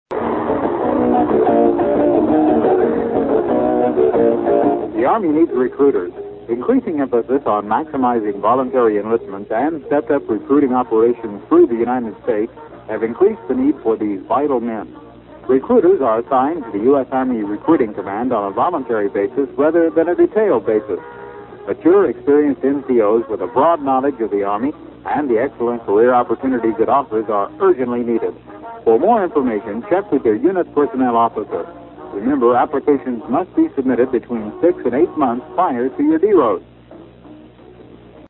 Recruiter PSA